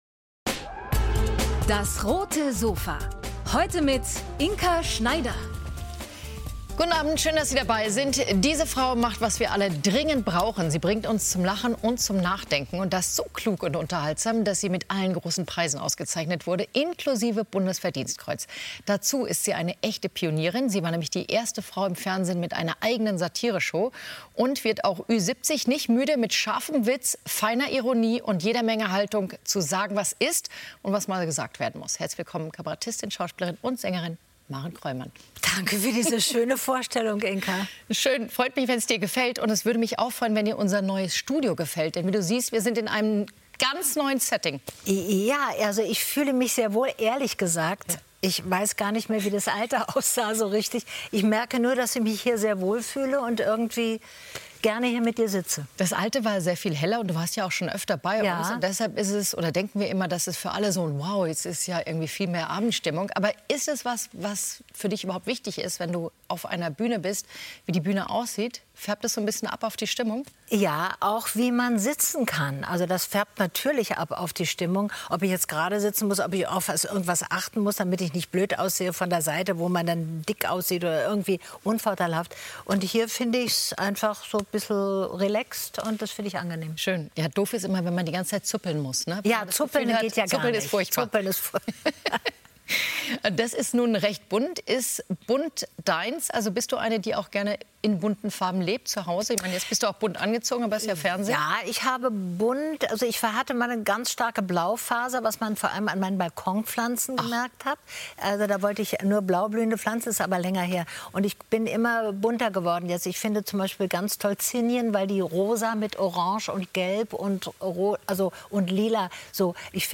Schauspielerin Maren Kroymann über die Kraft des Alters ~ DAS! - täglich ein Interview Podcast